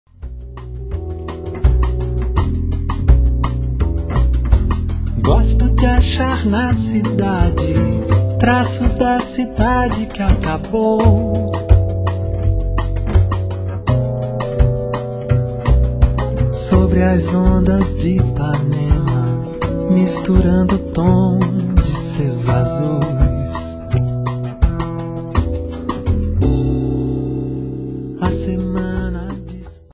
Fender Rhodes piano
double bass
una fluente bossanova